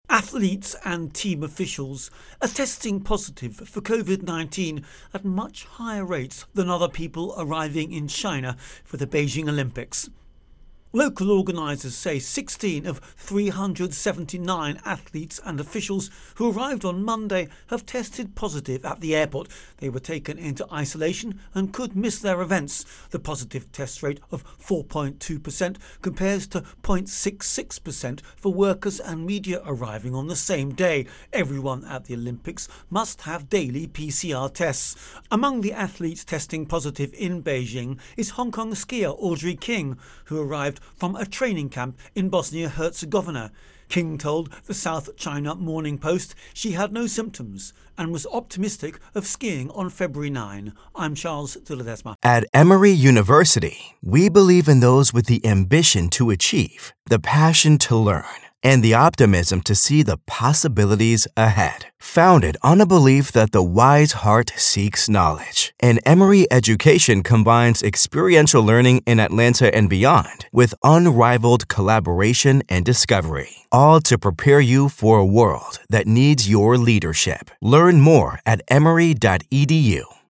Virus Outbreak-Olypmpics Intro and Voicer